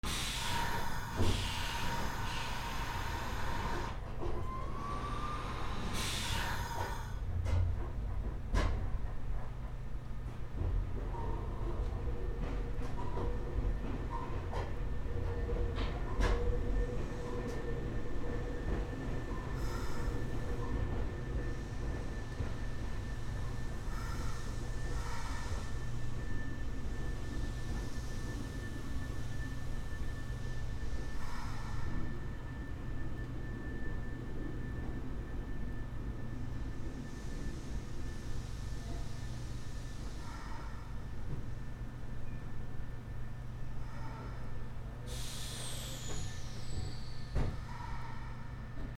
電車到着 車内より
/ E｜乗り物 / E-60 ｜電車・駅